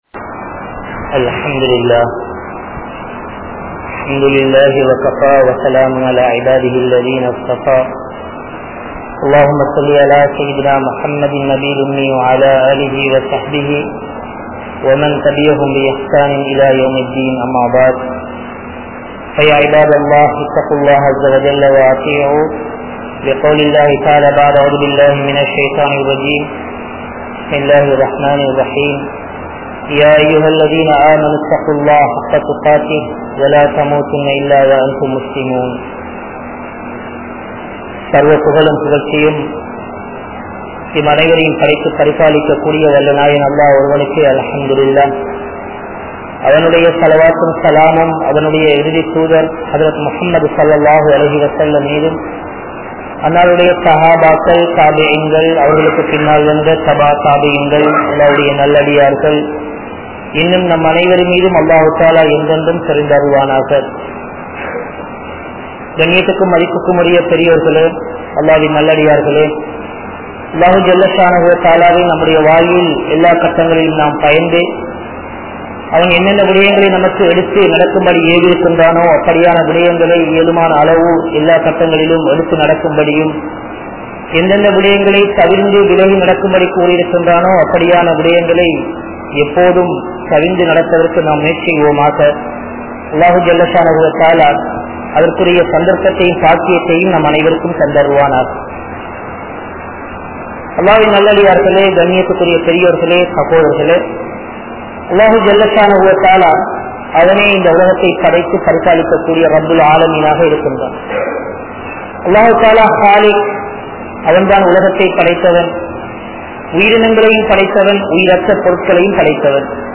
Naan Unmaiyaana Muslima? (நான் உண்மையான முஸ்லிமா?) | Audio Bayans | All Ceylon Muslim Youth Community | Addalaichenai
South Eastern University Jumua Masjith